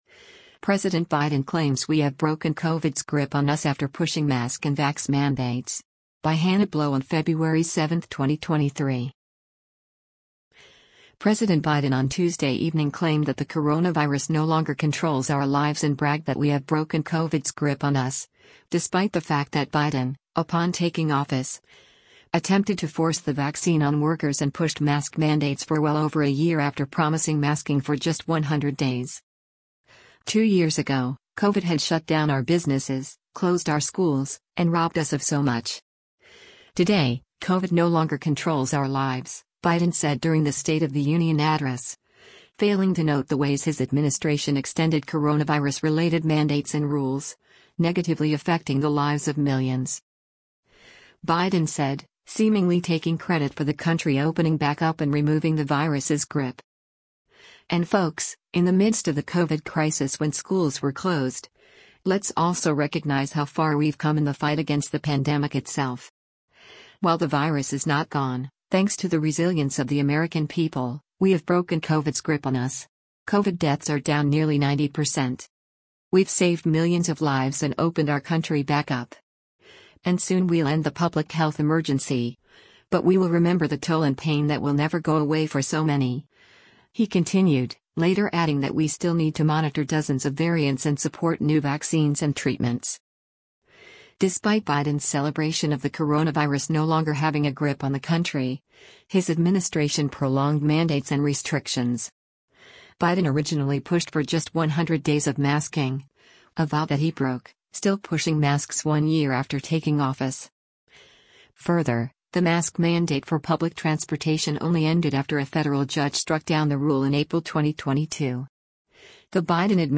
“Two years ago, COVID had shut down our businesses, closed our schools, and robbed us of so much. Today, COVID no longer controls our lives,” Biden said during the State of the Union address, failing to note the ways his administration extended coronavirus-related mandates and rules, negatively affecting the lives of millions.